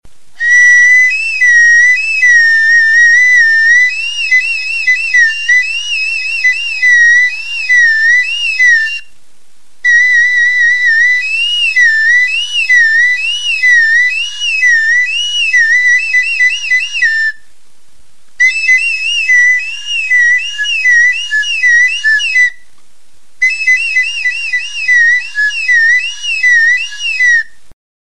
Music instrumentsTXULUBITA
Aerophones -> Flutes -> Fipple flutes (one-handed)
Lizar adar makilatxo batekin egindako txulubita da.
WOOD; ASH